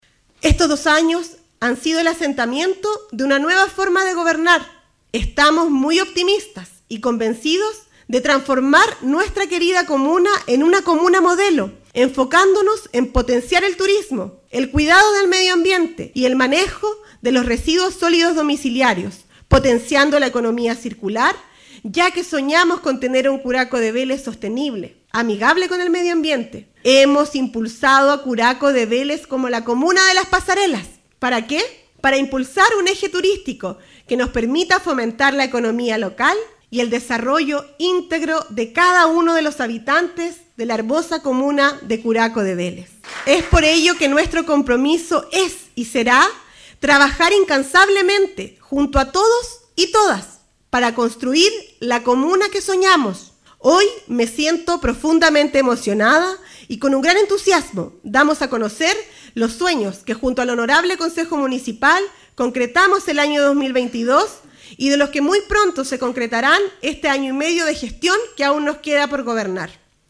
La ceremonia se desarrolló en dependencias del Centro Cultural, en el marco de una sesión extraordinaria del Concejo Municipal, en cumplimiento a la Ley Orgánica Constitucional de Municipalidades, donde la autoridad dio cuenta de la amplia cartera de proyectos gestionados y ejecutados durante su segundo año de mandato comunal y que favorecen en el desarrollo de los curacanos, con avances significativos en ejes como infraestructura pública y comunitaria; deportiva, cultural y de turismo, medioambiente, de salud y educativa, de servicios básicos, entre otras.